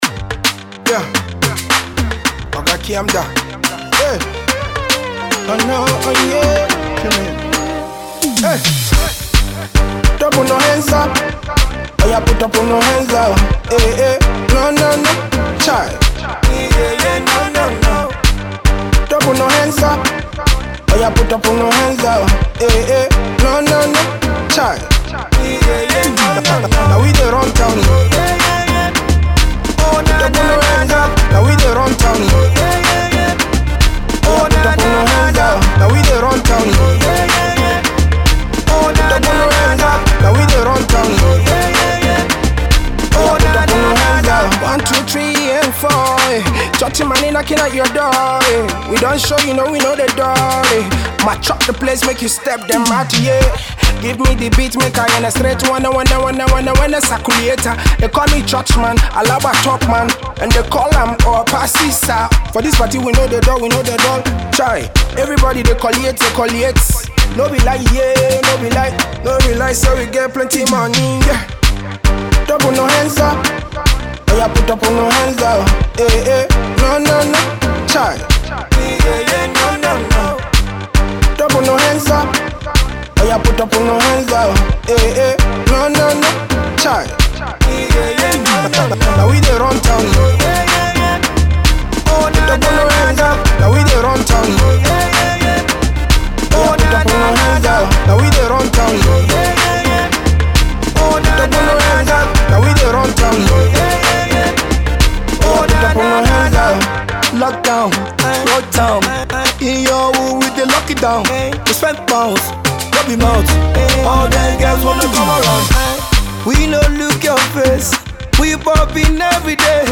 Street pop song